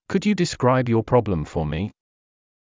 ｸｯ ｼﾞｭｰ ﾃﾞｨｽｸﾗｲﾌﾞ ﾕｱ ﾌﾟﾛﾌﾞﾚﾑ ﾌｫｰ ﾐｰ